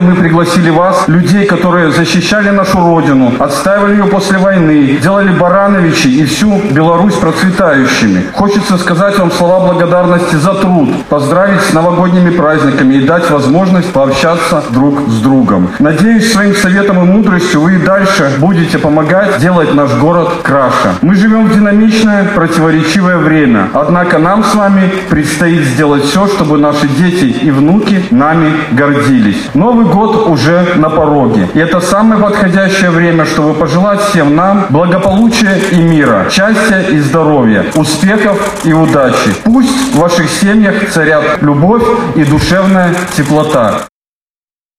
В городском Доме культуры собрались горожане, на которых направлена инициатива – пожилые люди.
С приветственным словом обратился заместитель председателя горисполкома Вадим Щербаков.